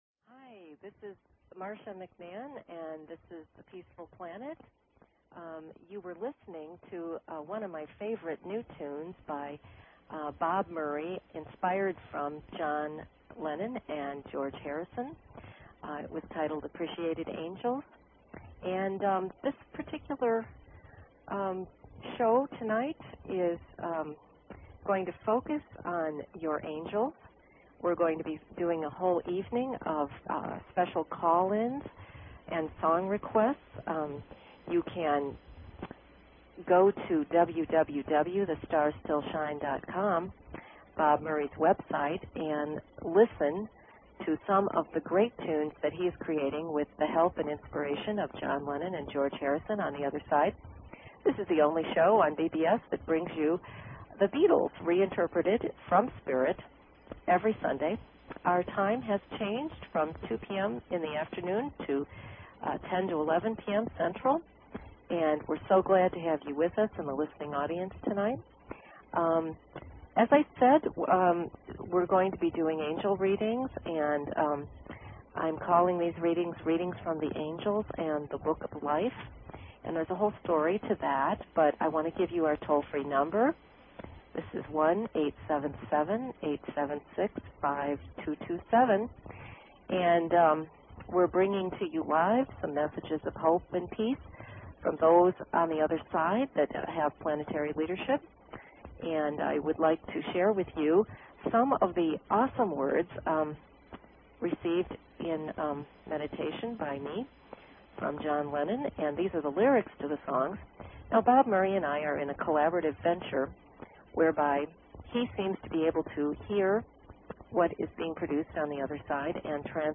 Talk Show Episode, Audio Podcast, Peaceful_Planet and Courtesy of BBS Radio on , show guests , about , categorized as